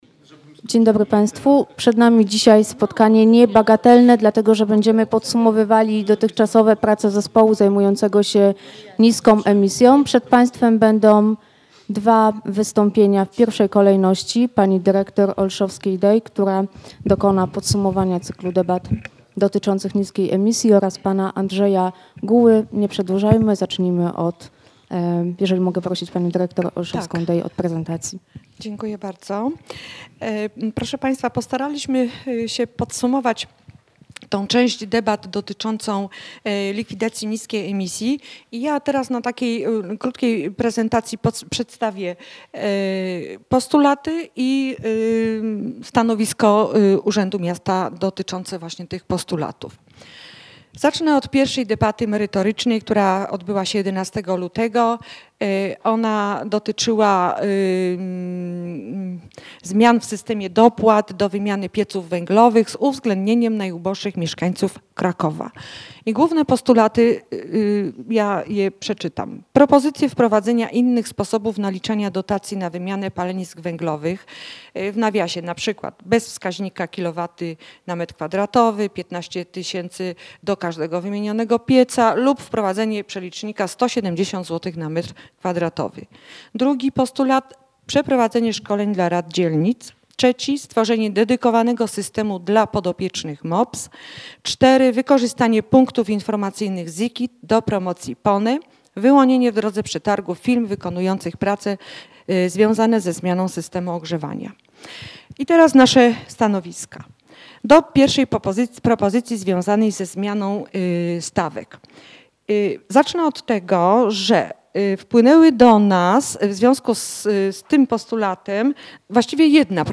Cykliczne spotkania Forum Na Rzecz Czystego Powietrza odbywały się w siedzibie Miejskiego Centrum Dialogu, przy ul. Brackiej 10.